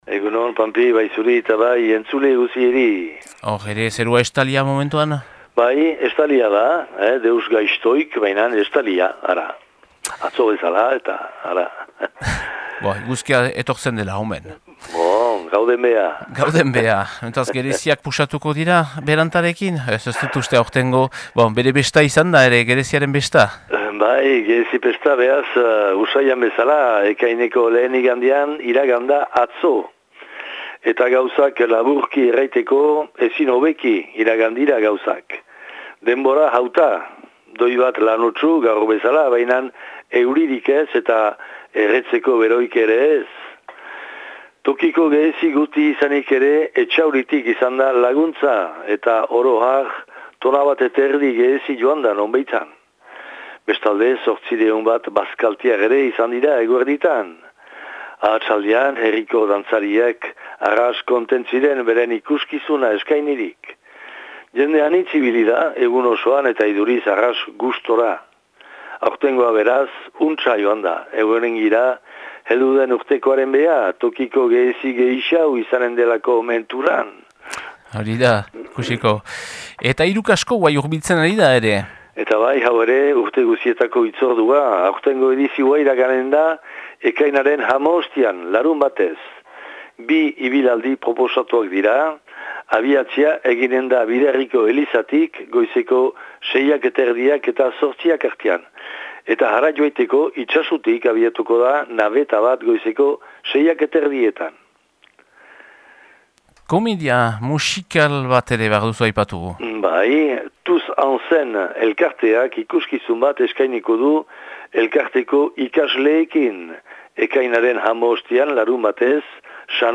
Ekainaren 3ko Itsasuko berriak